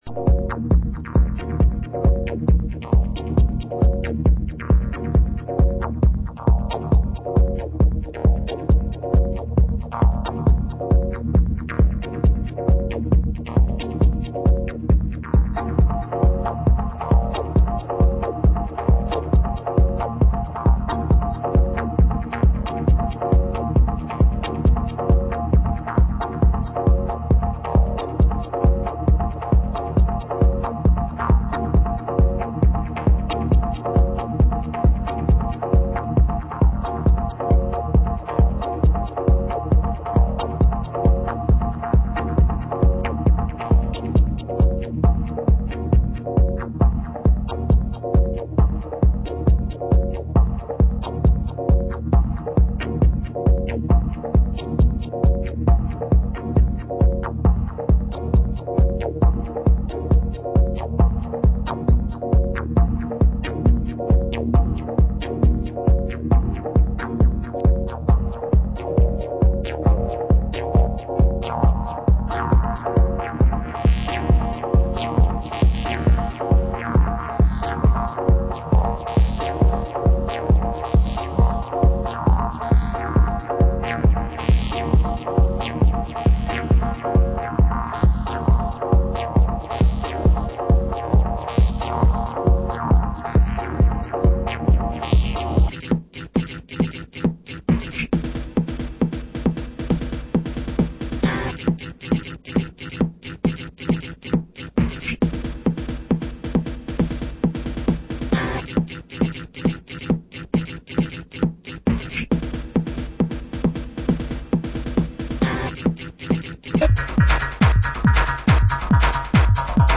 Genre: Detroit Techno